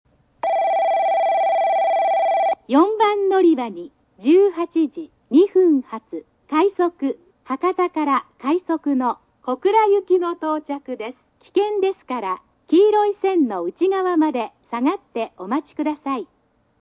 スピーカー：ソノコラム
接近放送（快速・小倉）　(82KB/16秒)
接近放送時に「危険ですから」のフレーズを喋らないのが特徴であり、形態は異なるものの、博多駅でもこのフレーズは存在しない。